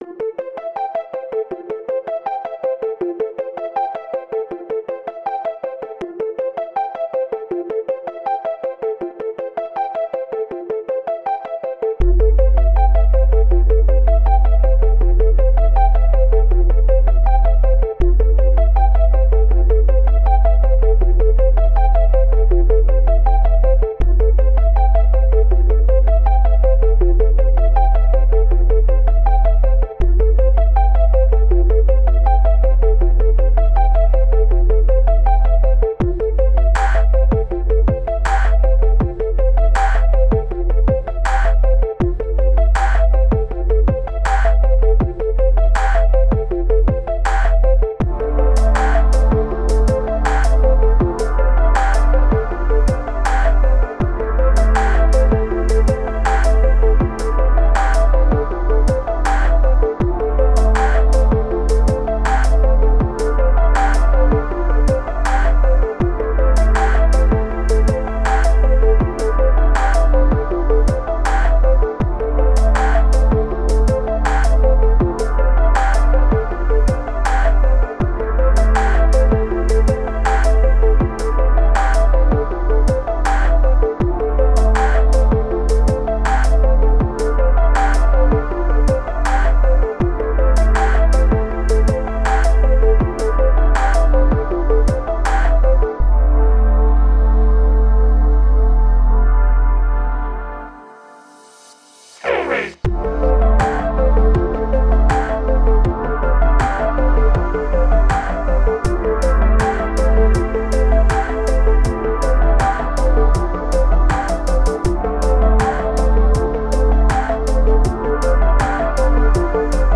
It was retro gaming and 80s themed, so I thought Synthwave would fit perfectly.
synthwave theme 80s electronic_music